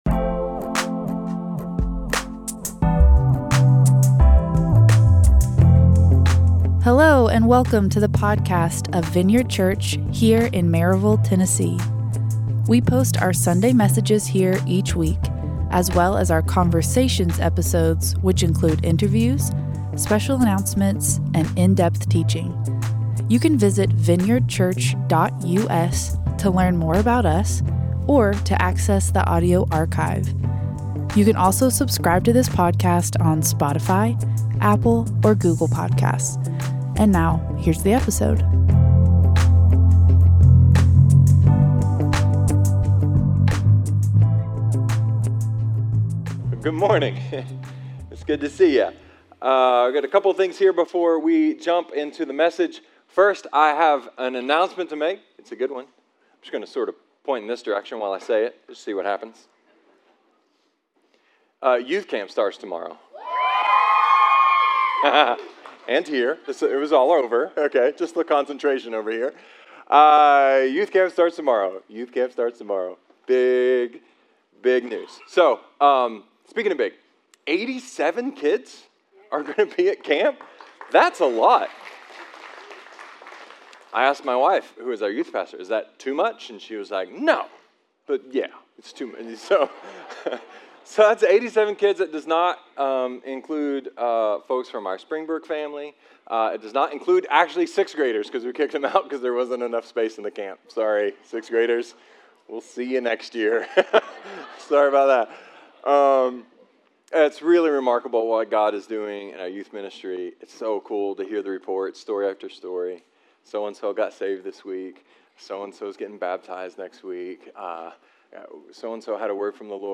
A sermon about grammar, kites and community (plus a building update!)